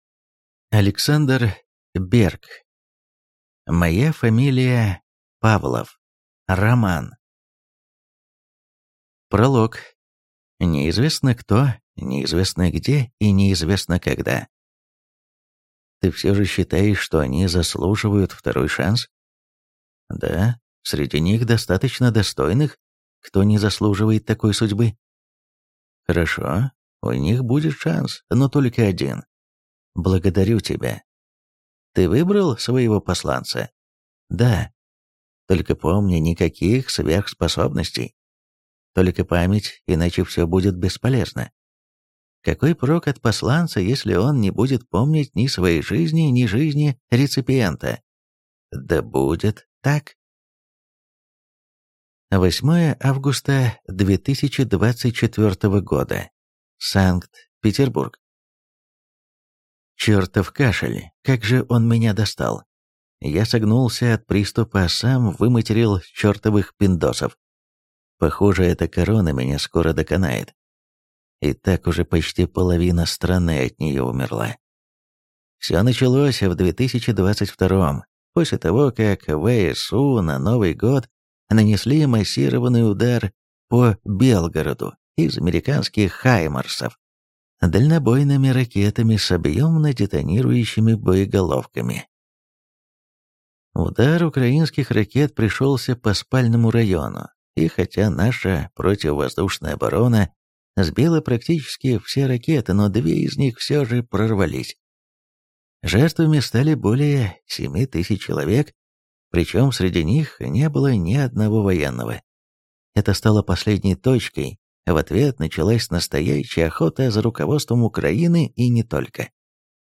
Аудиокнига Моя фамилия Павлов | Библиотека аудиокниг